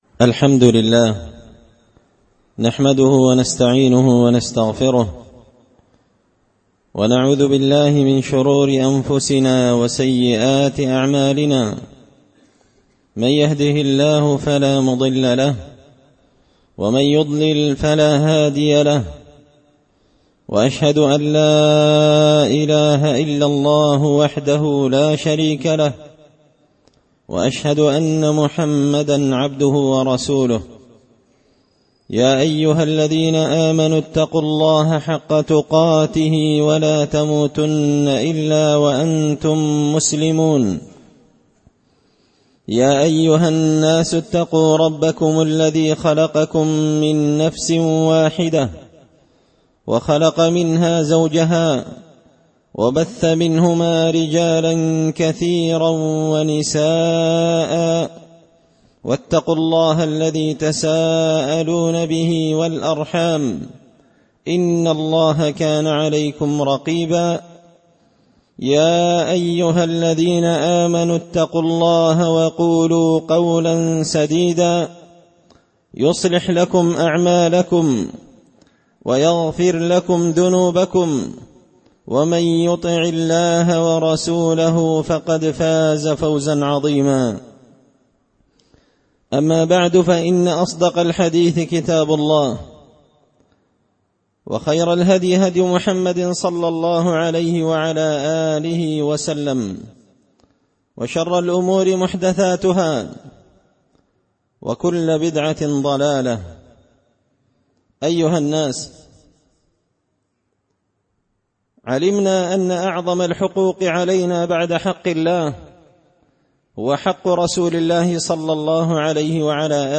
خطبة جمعة بعنوان – حق الرسول صلى الله عليه وسلم الجزء الثاني
دار الحديث بمسجد الفرقان ـ قشن ـ المهرة ـ اليمن